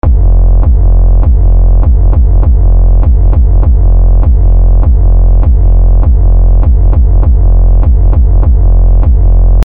Aus diesem dröhnenden Klang (Preset „Nerd Head“ für den Sampler) …